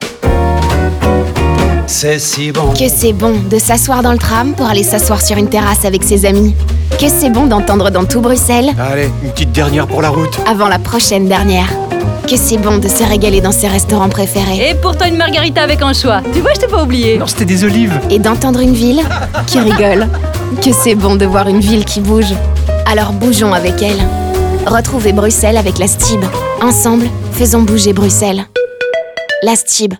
Spot radio 1